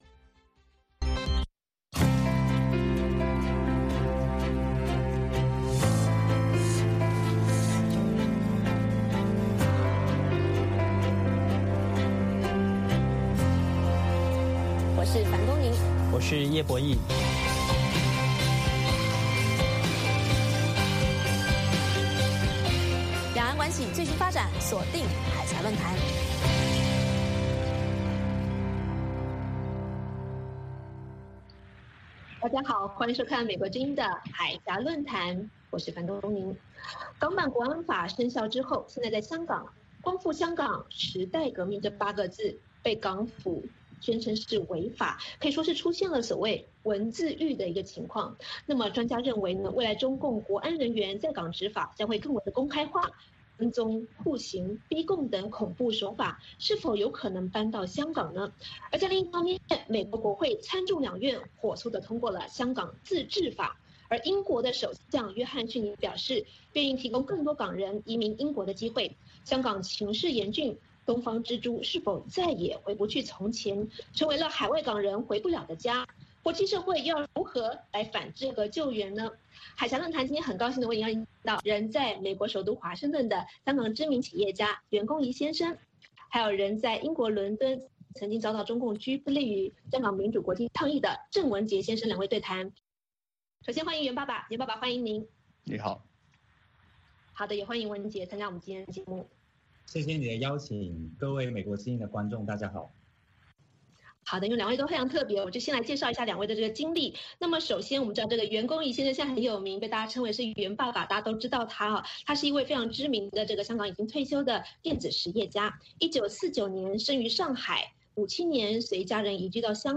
美国之音中文广播于北京时间早上8-9点重播“海峡论谈”节目。《海峡论谈》节目邀请华盛顿和台北专家学者现场讨论政治、经济等各种两岸最新热门话题。